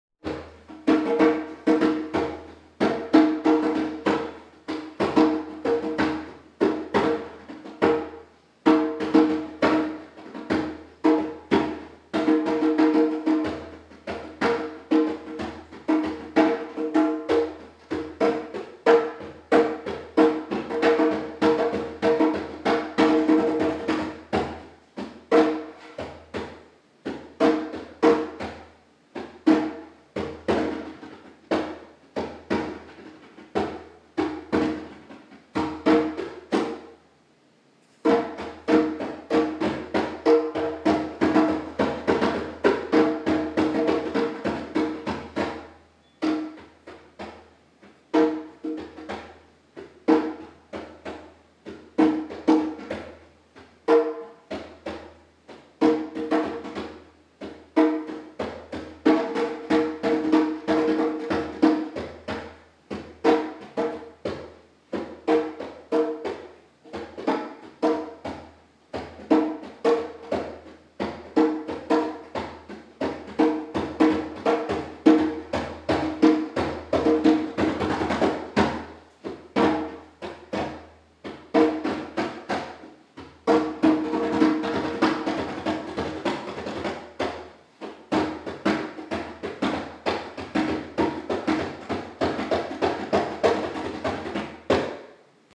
Bluesy Djembe
bluesy-djembe.m4a